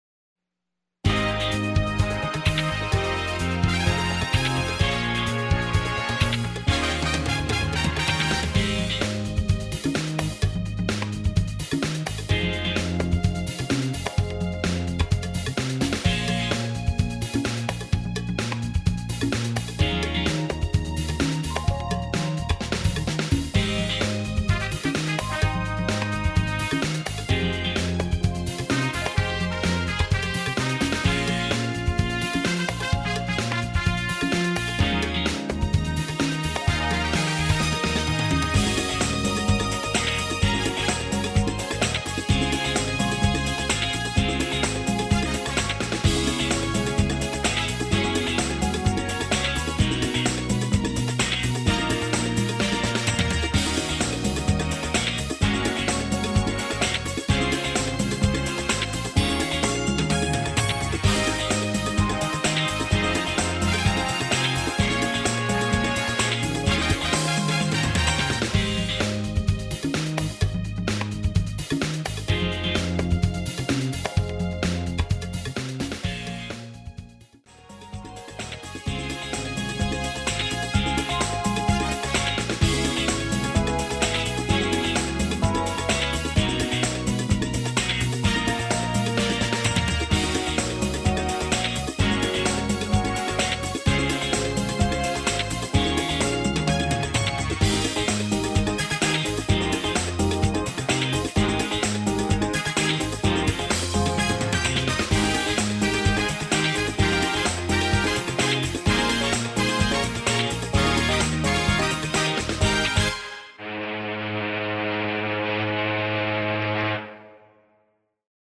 Sing Along Version